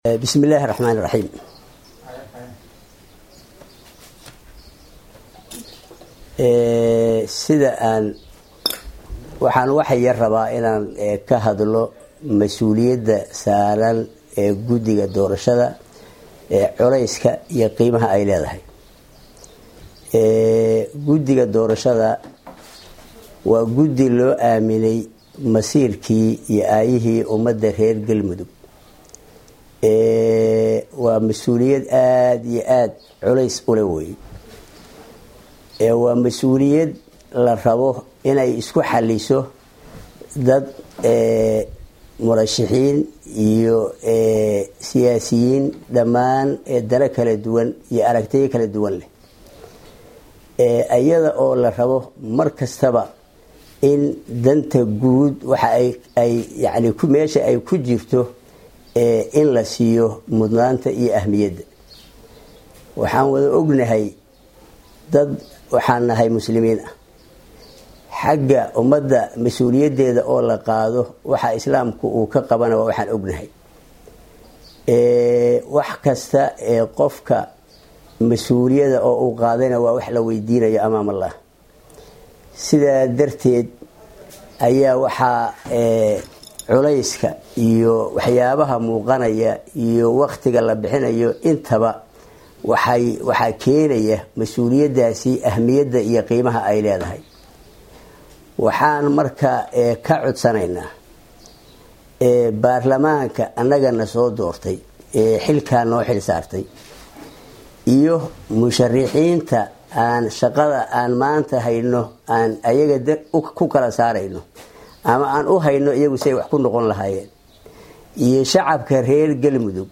Halkan ka dhagayso codka gudoomiyaha guddiga doorashada Galmudug.